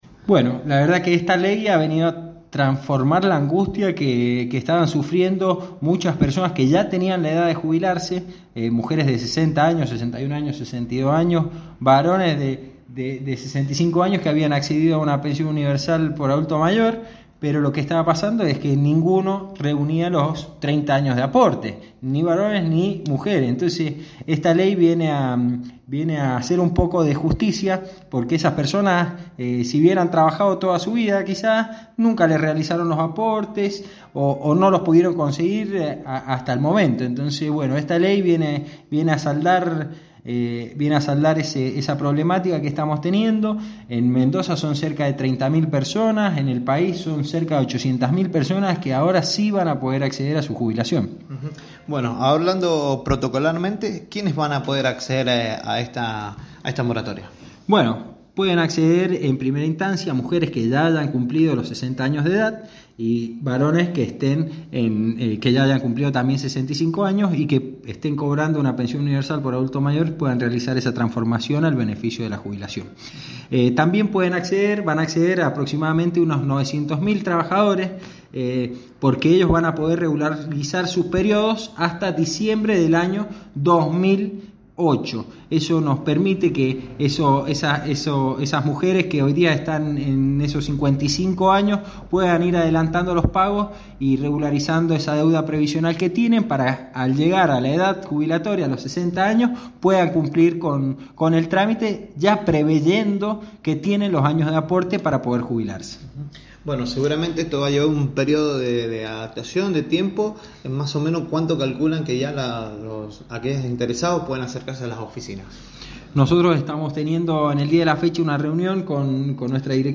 Este lunes por la mañana, se dió inicio a la temporada 2023 de «Proyección 103», el programa radial emitido por  fm 103.5 General San Martín.